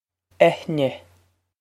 Eithne Eh-nyeh
Pronunciation for how to say
This is an approximate phonetic pronunciation of the phrase.